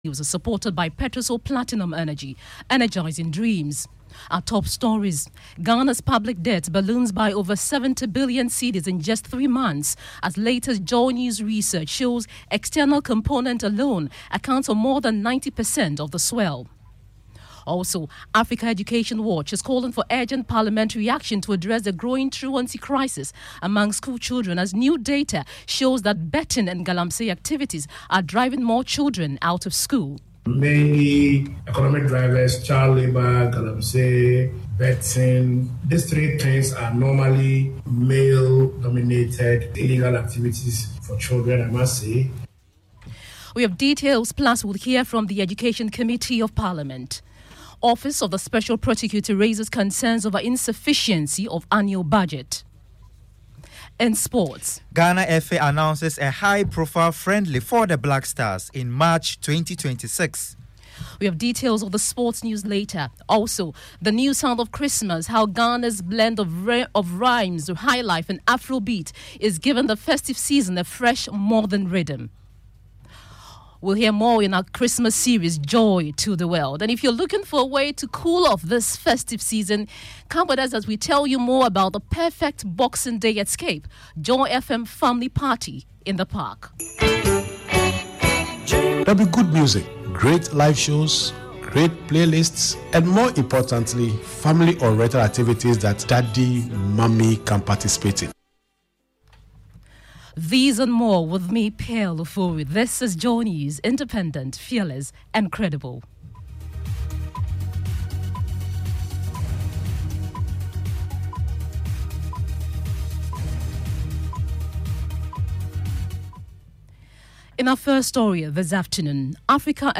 Joy Fm Afternoon News Bulletin